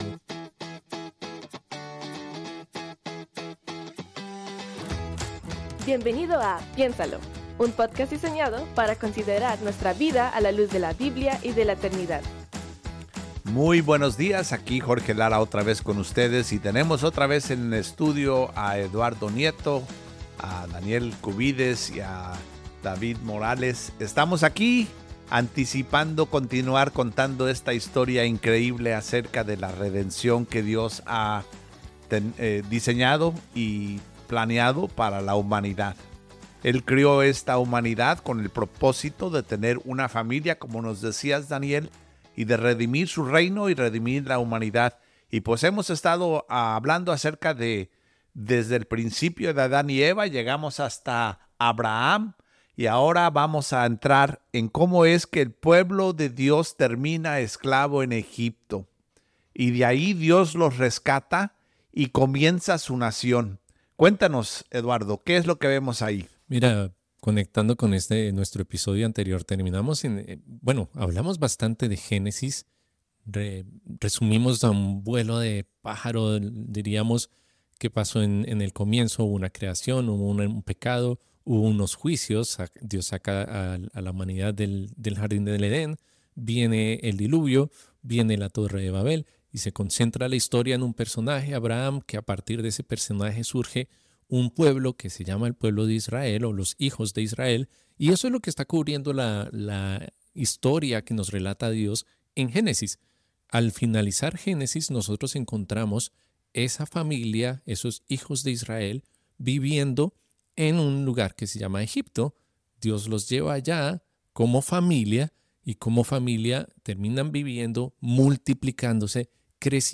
conversan en torno al contenido de la Biblia, abordanto el Antiguo Testamento